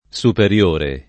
SuperL1re] agg.